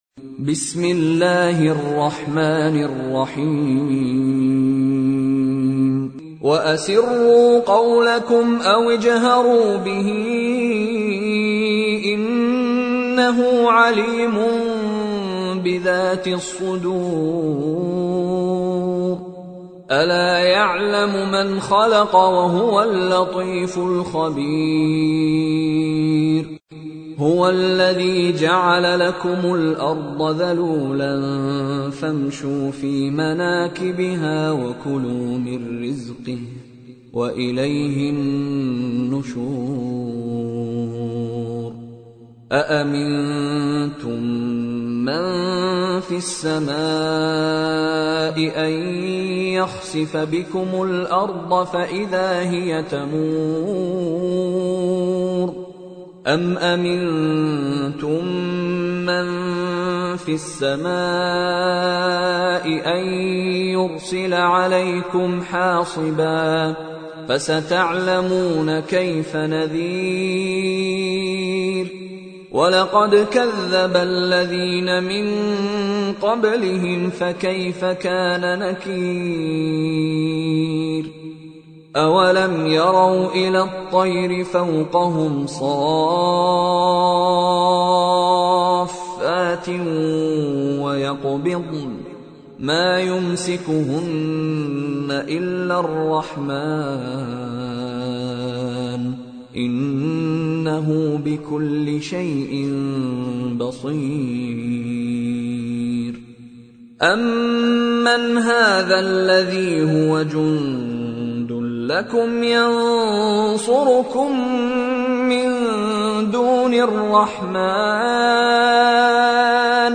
پخش آنلاین و دانلود قرائت آیات هفته دوم شهریور ماه ، قرآن پایه ششم ، با قرائت زیبا و روحانی استاد مشاری رشید العفاسی
قرائت